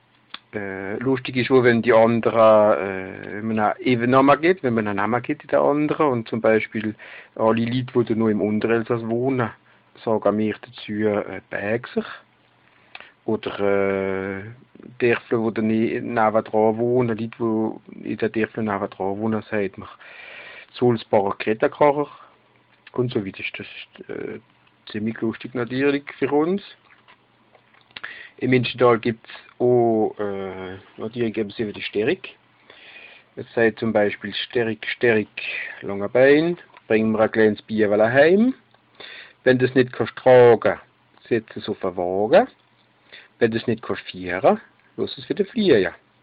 My suggestion was based on the fact that in words where standard German has ü this reading has i, which led my to think that it is probably a German dialect spoken in a border region with a romance languages, but I didn’t think of Alsatian.